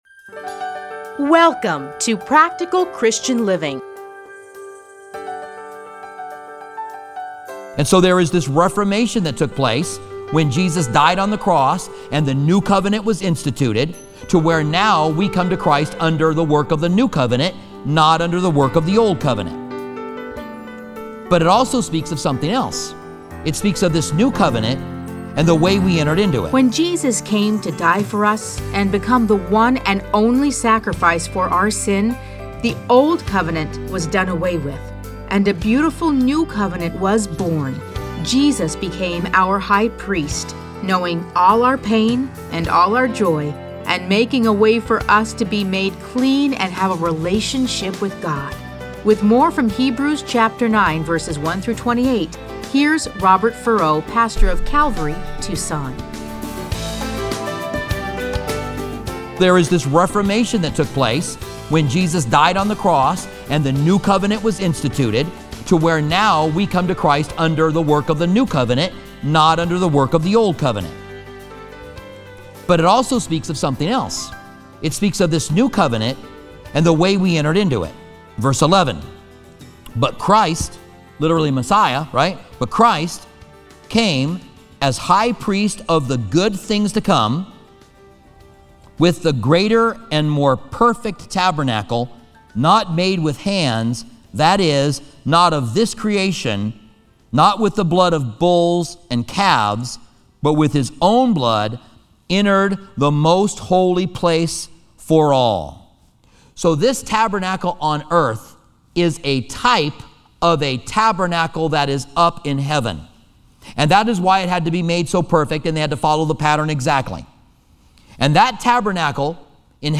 Listen to a teaching from Hebrews 9:1-28.